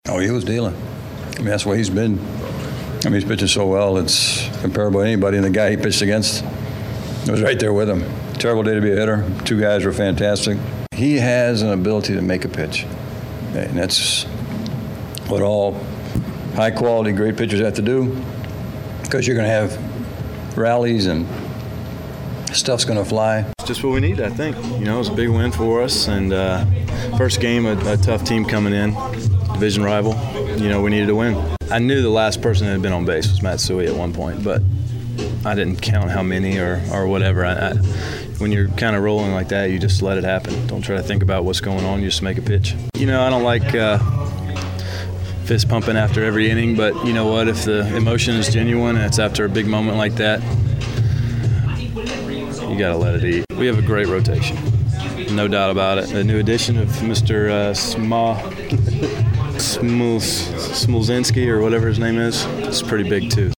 Click below to listen to clubhouse reaction from Wainwright and Tony LaRussa.